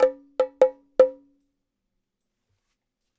Bongo Fill 01.wav